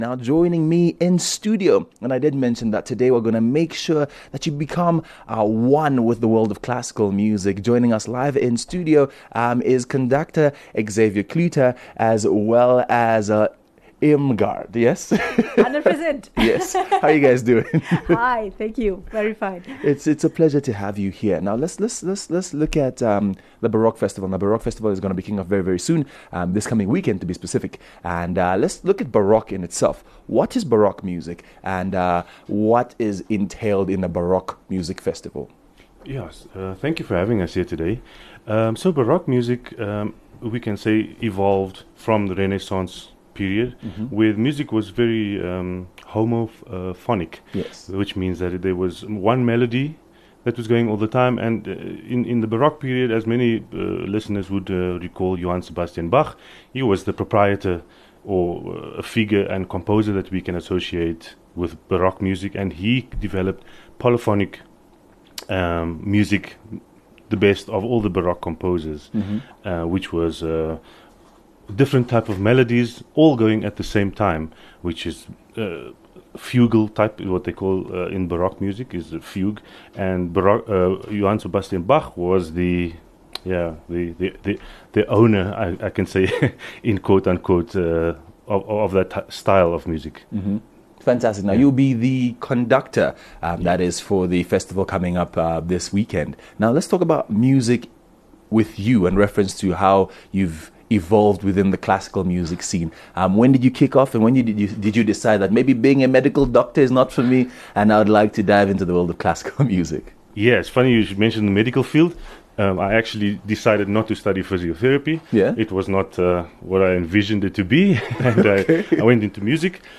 live in Studio to chat Baroque music and the festival.